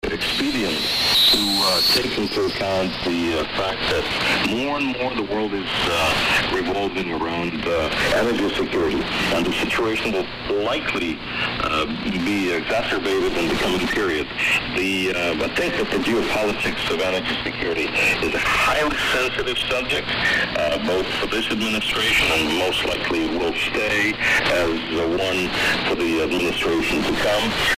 Sound bytes - Here are 3 audio snippets recorded on a Zoom H4 professional digital recorder of the same frequency over a period of a couple of minutes using the Eton E100, the Eton E1 and the Sony 2010:
The Eton E100 filter sounds, to my ear, about 2.2khz or so - The Eton E1 (in all fairness) was set at 7khz w/ Sync lock on so it sounded a tad strident.